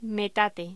Locución: Metate
voz